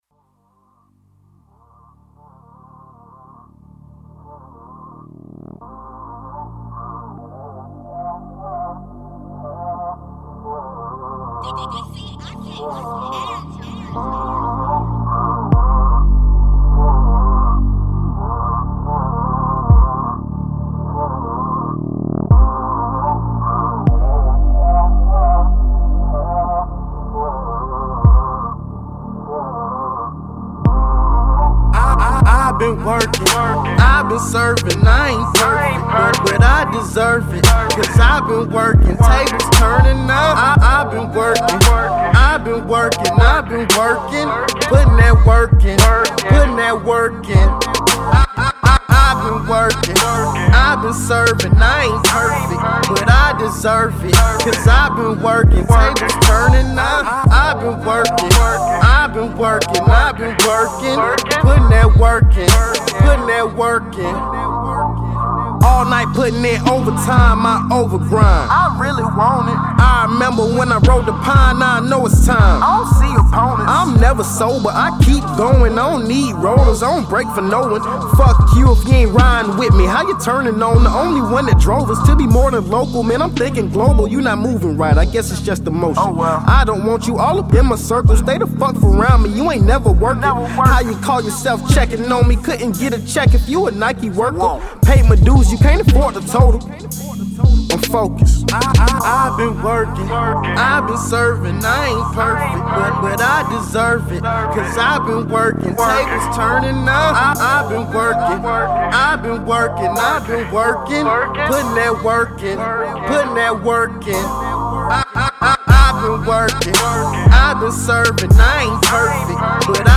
Smooth track, exemplifying and promoting hard work